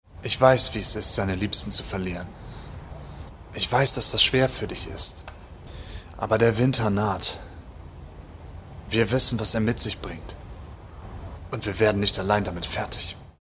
Sprachproben